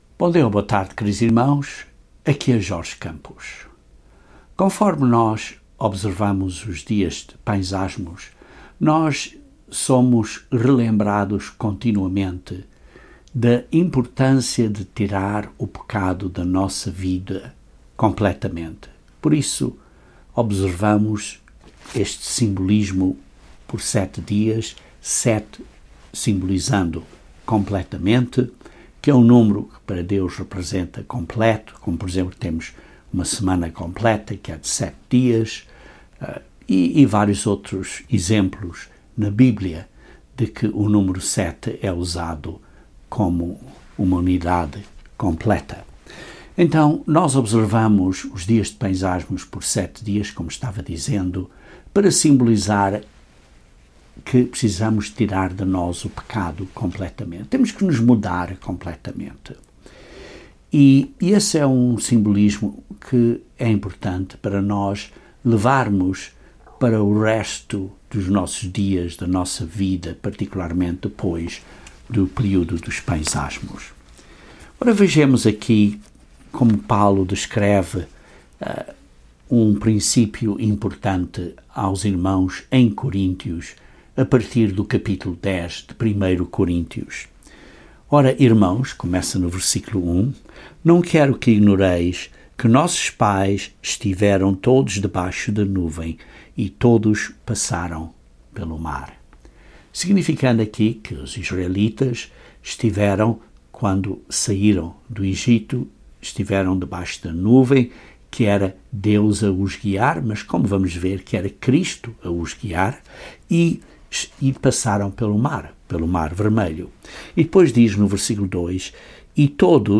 O apóstolo Paulo menciona que o mau exemplo deles serve como uma admoestação para nós, a fim de aprendermos com esse exemplo e não sermos como eles. Este sermão, portanto, aborda o novo Espírito que devemos ter e como ele deve refletir-se em nossa conduta, como um "novo homem".